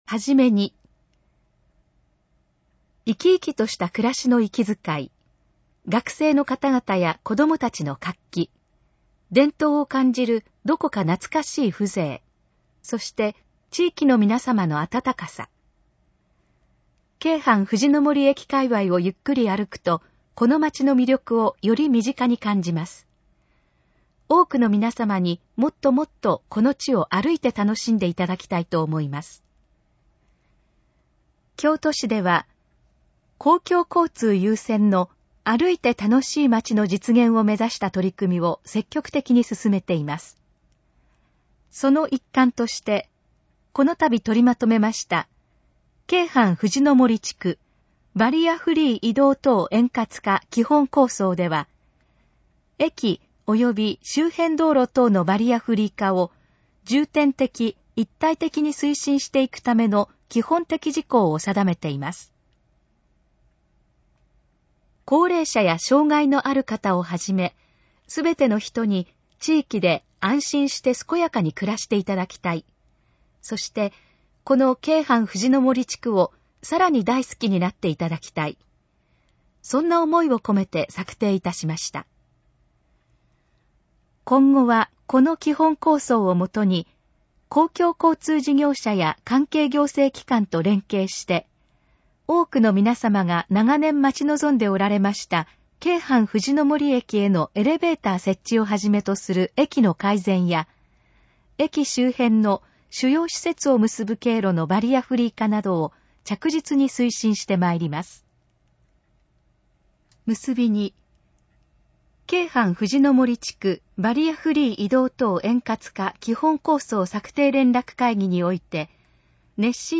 このページの要約を音声で読み上げます。
ナレーション再生 約550KB